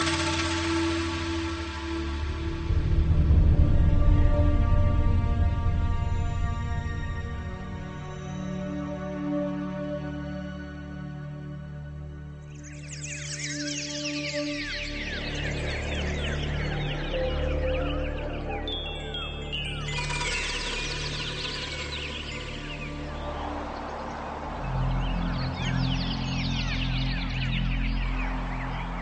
birds-and-sea.mp3